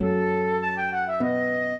minuet8-6.wav